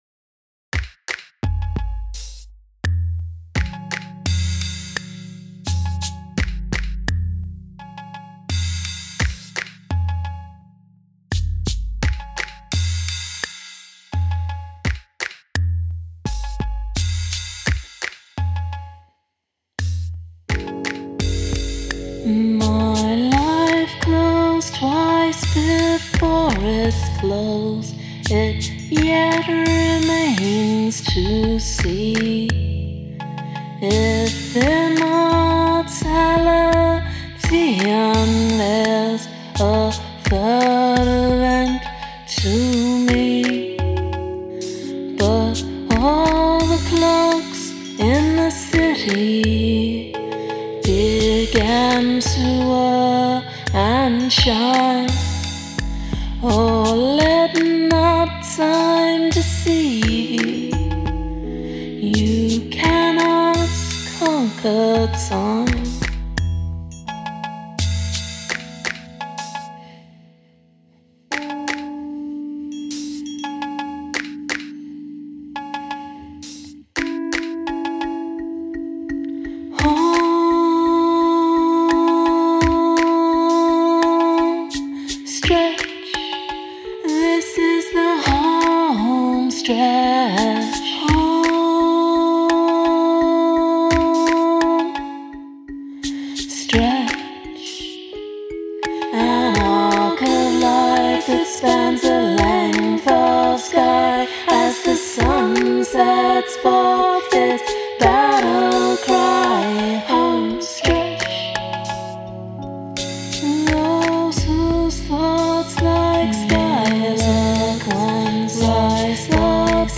Polyrhythm